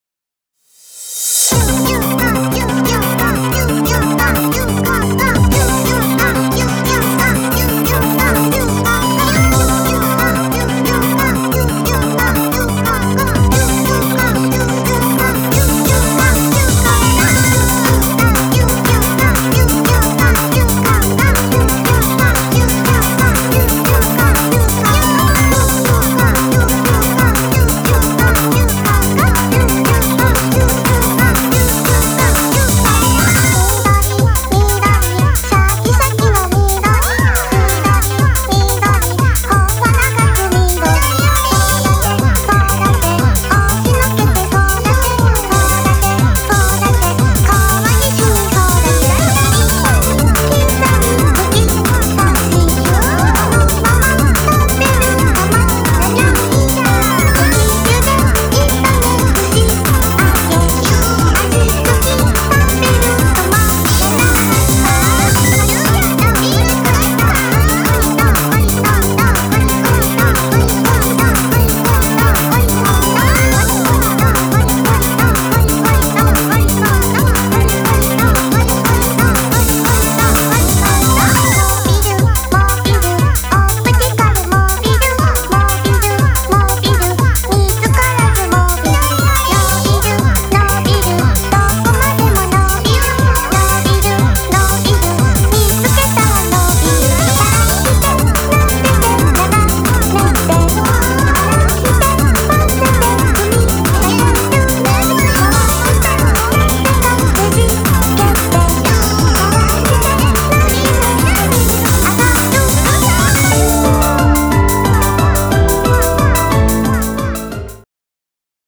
BPM180
Audio QualityPerfect (High Quality)
this is a cute song with a lot of repetition